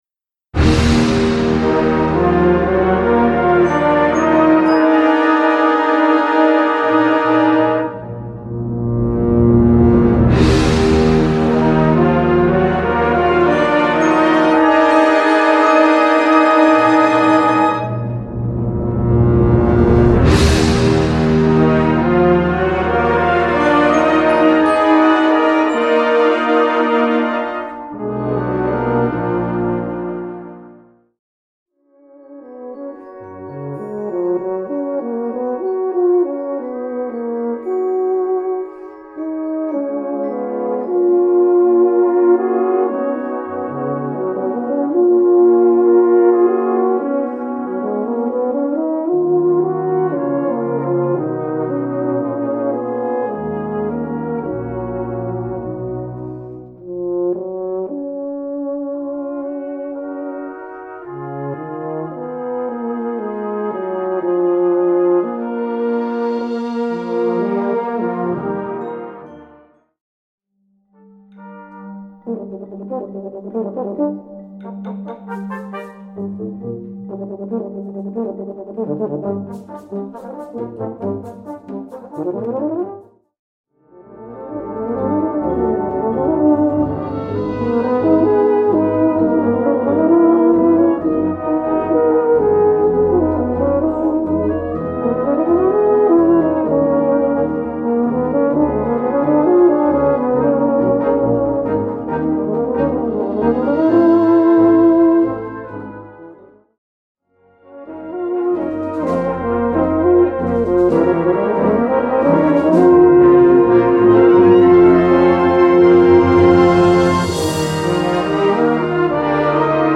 Euphonium solo